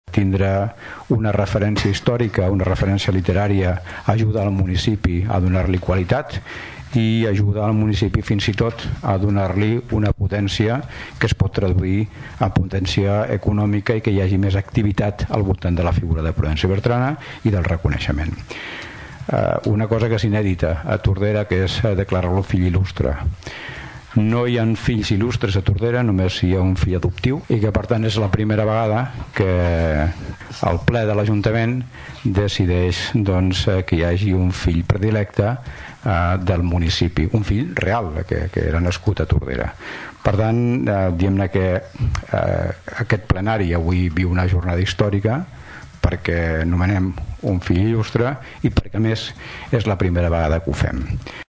L’Alcalde de Tordera, Joan Carles Garcia, ha destacat el valor que té vincular una referència cultural a Tordera.